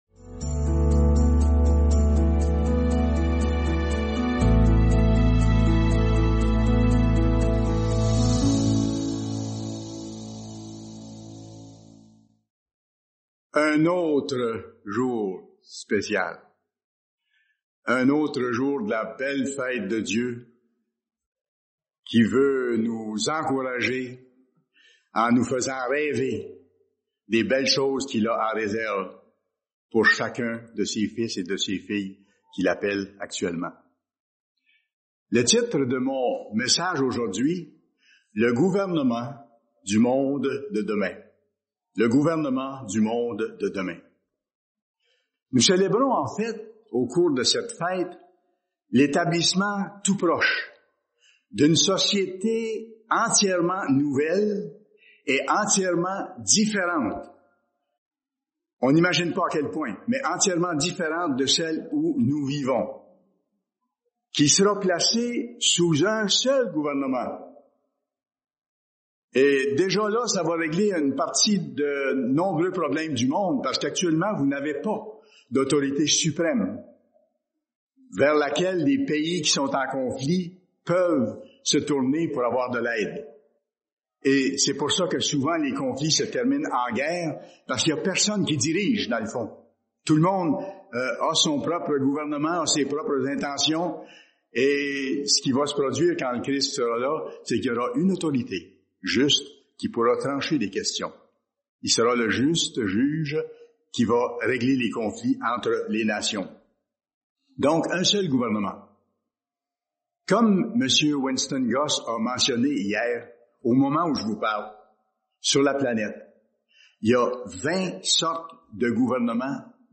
Fête des Tabernacles – 2e jour